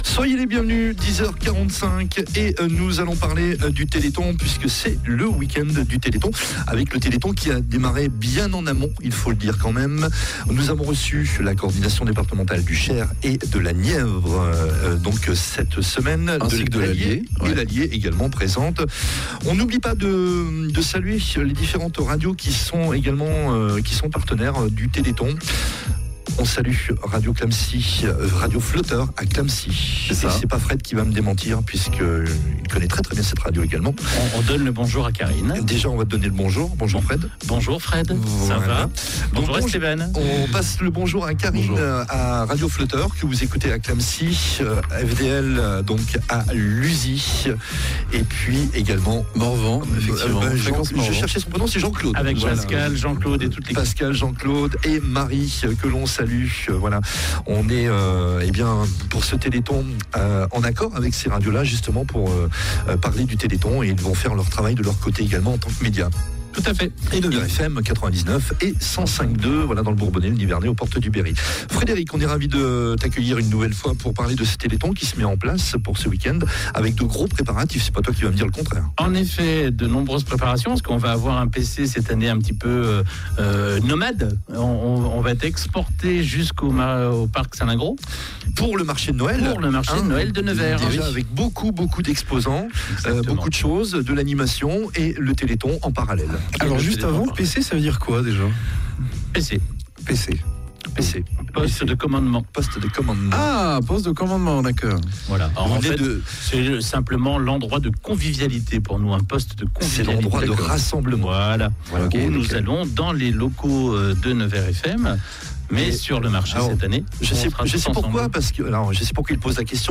Diffusé en direct le Jeudi 7 Décembre